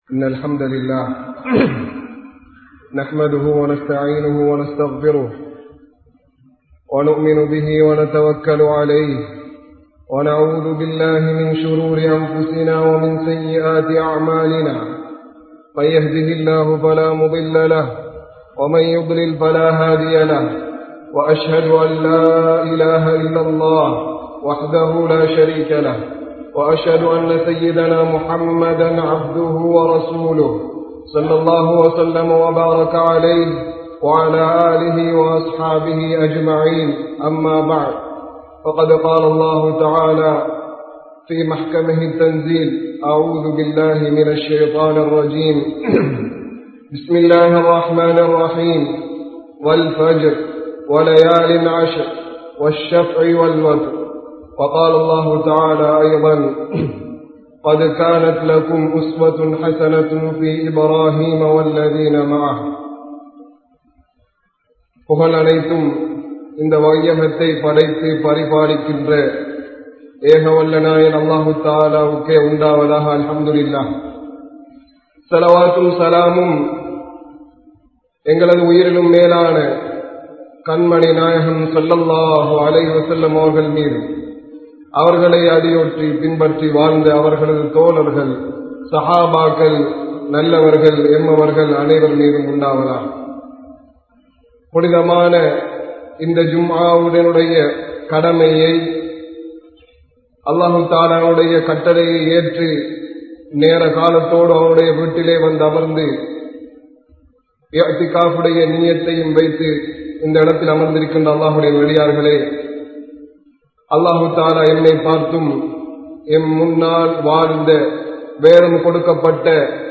Ibrahim(Alai)Avarhalin MunMaathirihal (இப்றாஹீம்(அலை)அவர்களின் முன்மாதிரிகள்) | Audio Bayans | All Ceylon Muslim Youth Community | Addalaichenai
Mohideen Grand Jumua Masjith